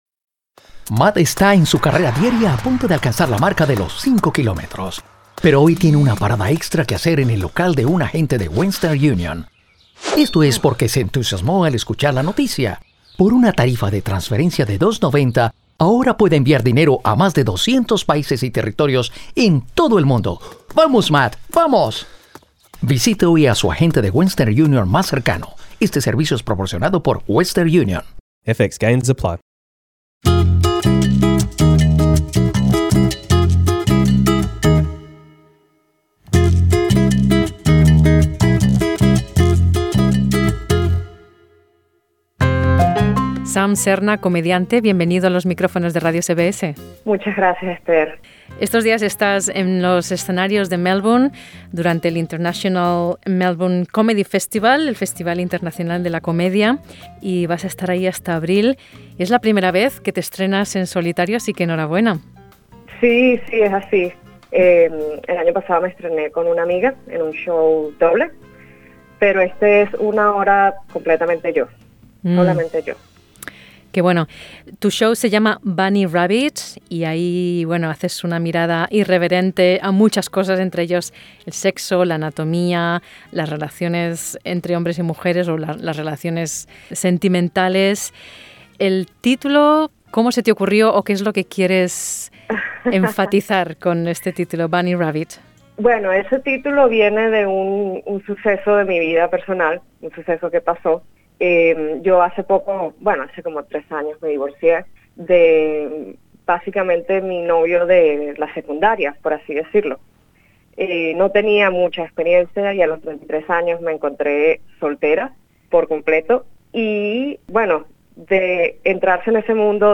Escucha la entrevista completa presionando la imagen principal.